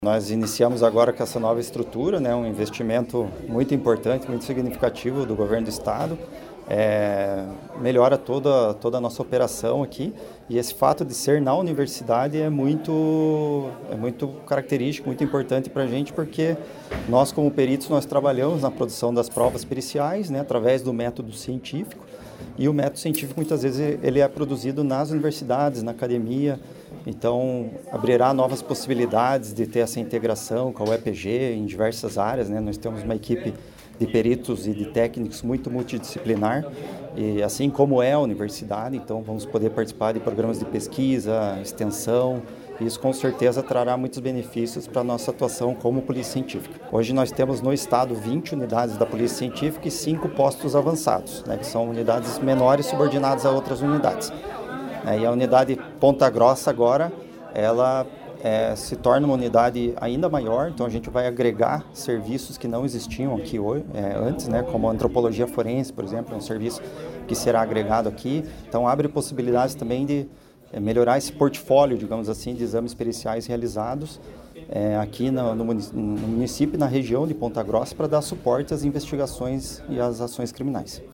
Sonora do diretor-geral da Polícia Científica do Paraná, Ciro Pimenta, sobre a nova unidade da Polícia Científica em Ponta Grossa